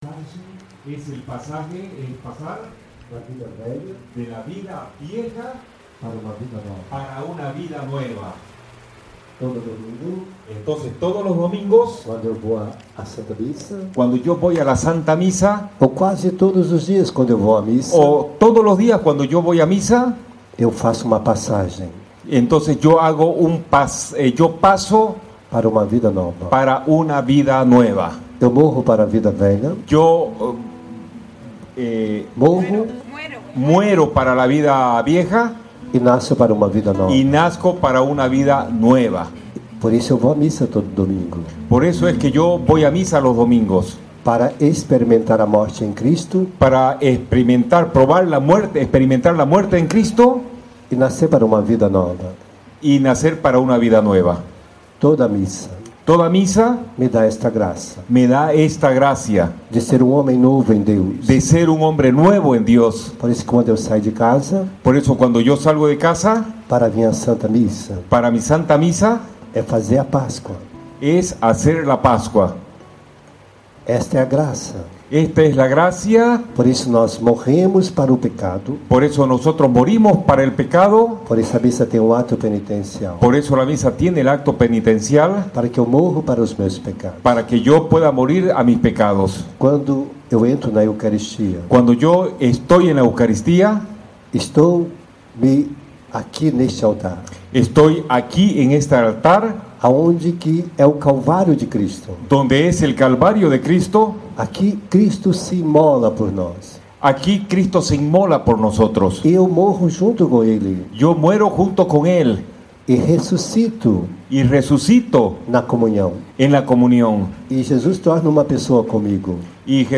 Retiro Carismático Católico: "El Justo Vivirá por la Fé" Realizado del 10 al 13 de Febrero del 2017 en la Casa de Retiro Juan XXIII, ubicado en Santa Cruz, Bolivia.